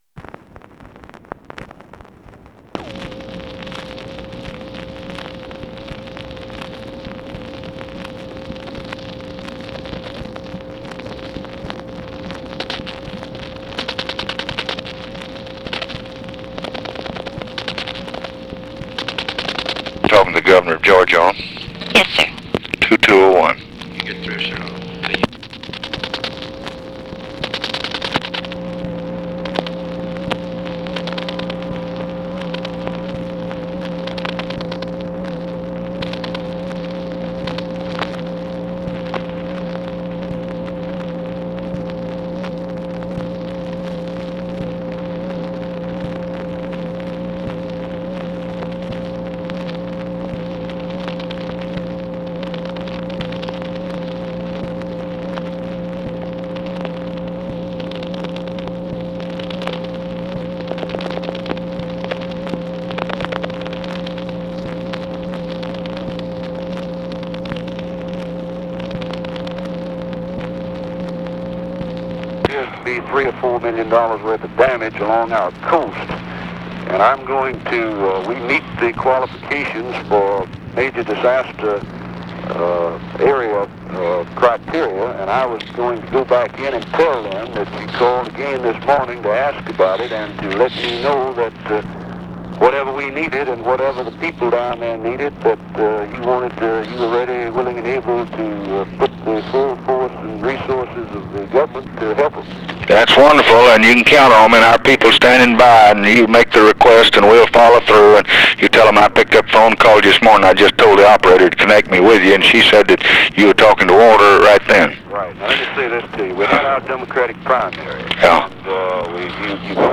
Conversation with CARL SANDERS, WALTER JENKINS and JACK VALENTI, September 10, 1964
Secret White House Tapes